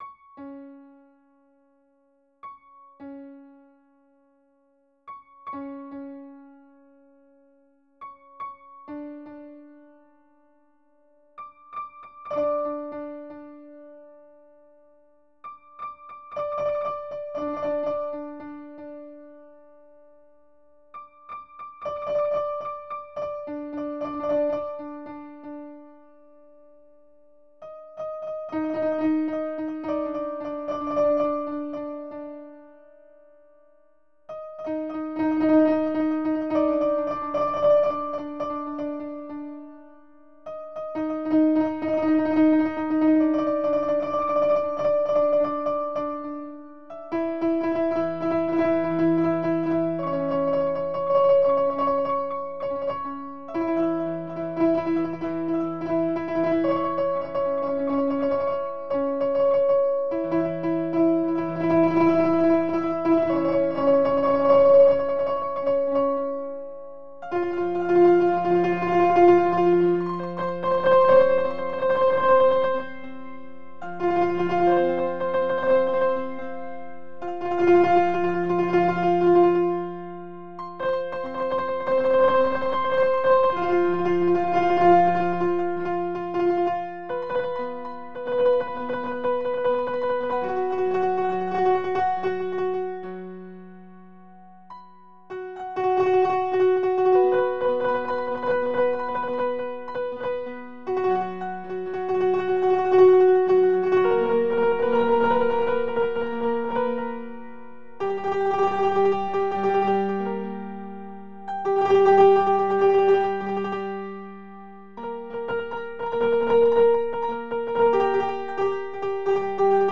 (pour piano)